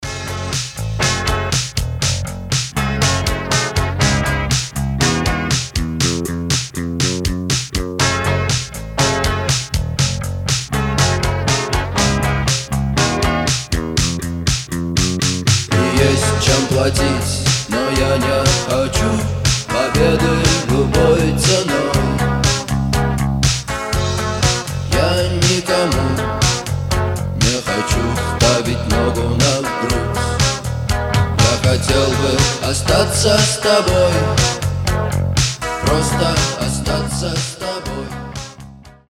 • Качество: 320, Stereo
80-е
new wave
пост-панк